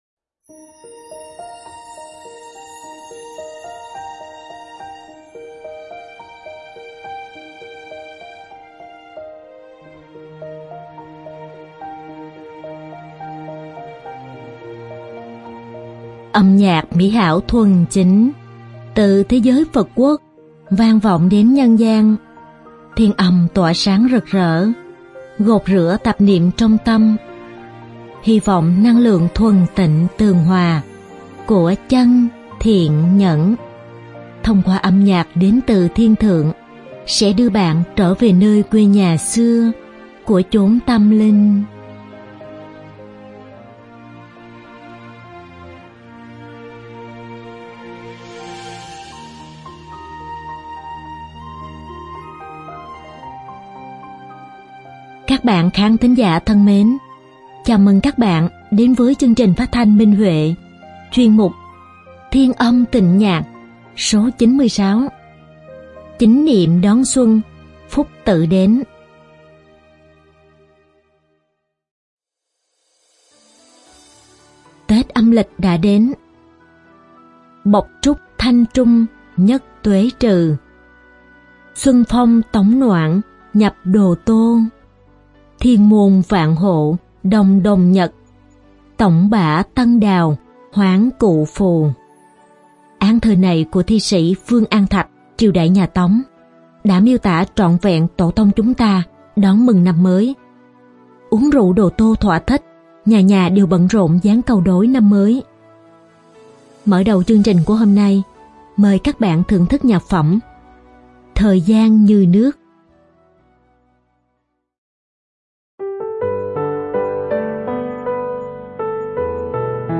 Đơn ca nữ
Hợp xướng